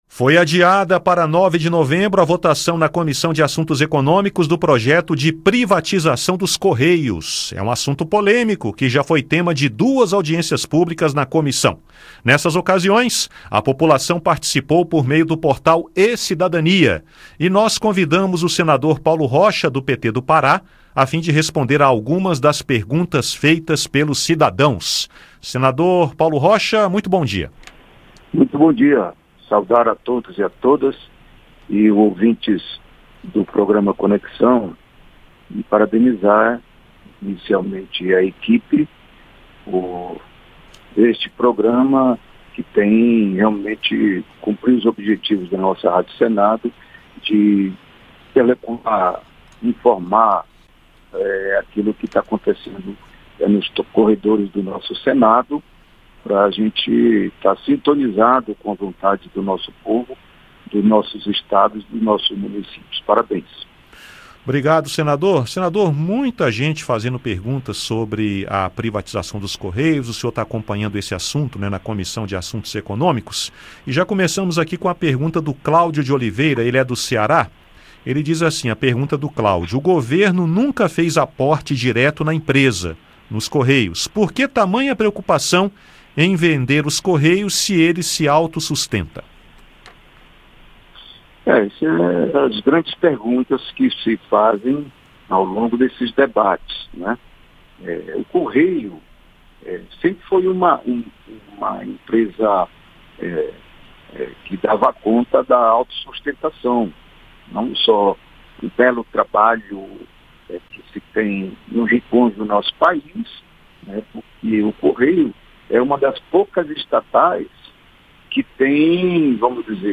O senador Paulo Rocha (PT-PA) acredita que privatizar não vai resolver os problemas do governo e sugeriu a realização de uma terceira audiência pública para aprofundar o debate. O Conexão Senado convidou o senador para responder a algumas das perguntas feitas pelos cidadãos na audiência.